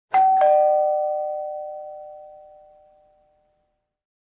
Ding Dong
, the doorbell rung.